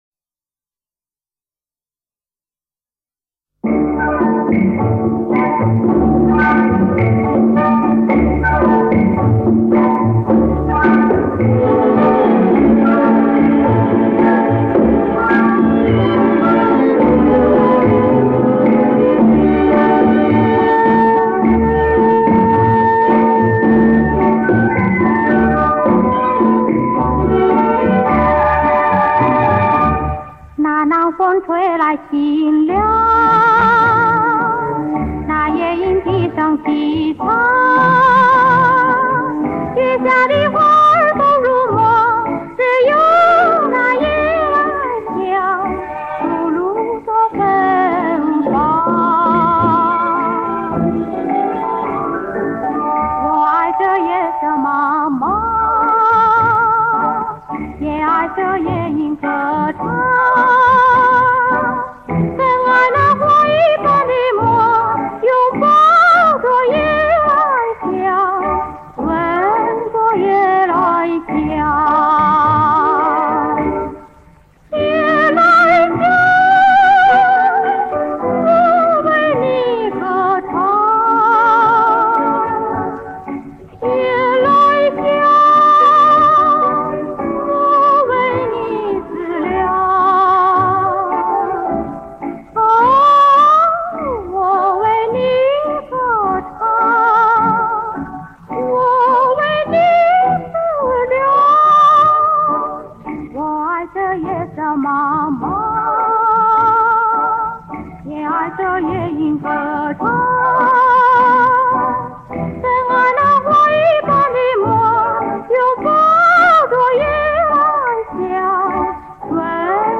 婉转动人，歌唱造诣高深，
中国四十年代最具名气的流行女歌手。